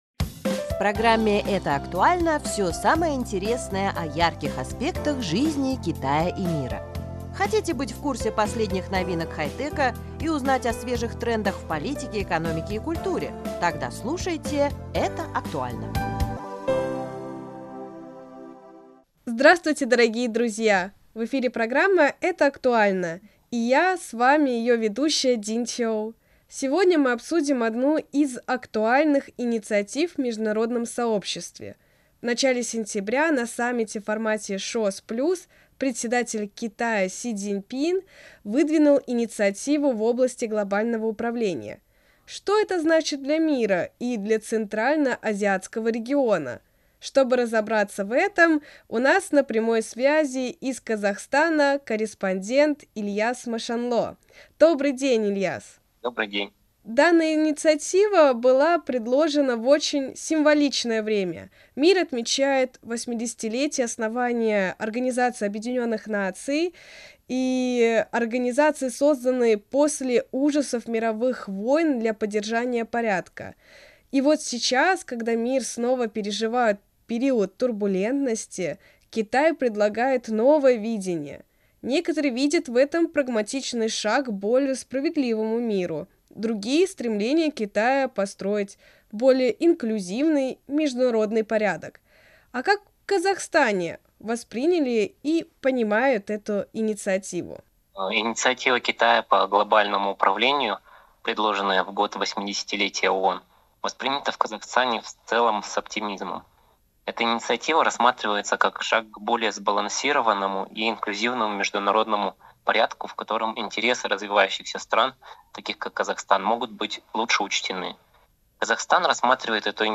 В эфире программы «Это актуально!» мы разбираемся в этих актуальных вопросах с нашим гостем